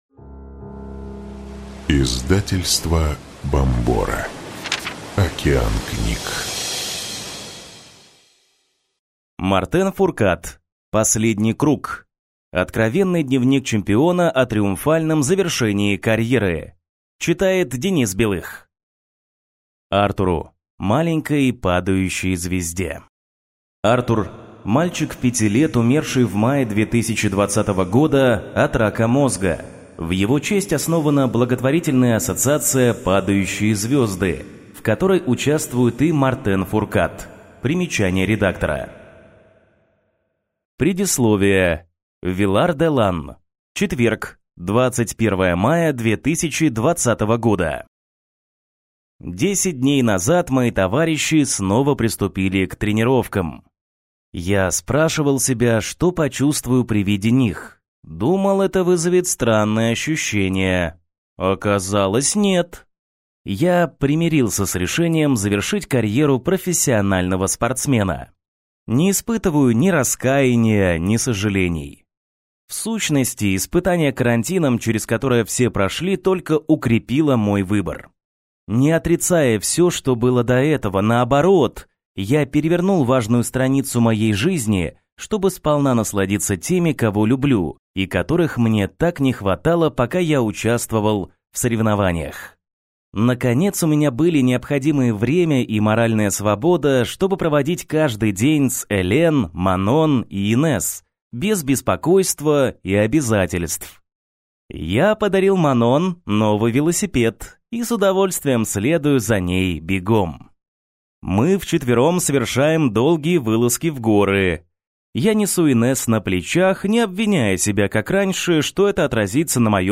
Аудиокнига Последний круг. Откровенный дневник чемпиона о триумфальном завершении карьеры | Библиотека аудиокниг